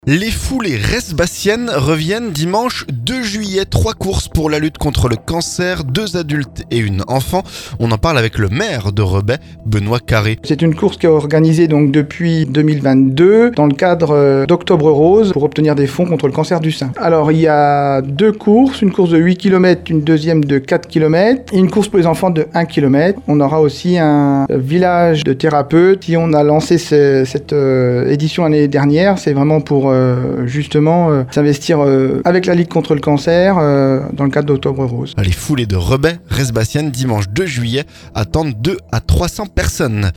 On en parle avec le maire de Rebais Benoit Carré.